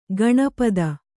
♪ gaṇa pada